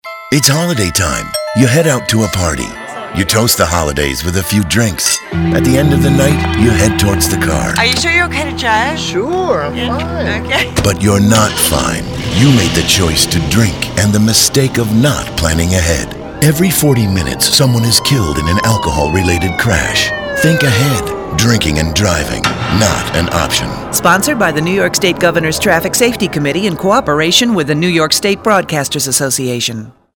English Radio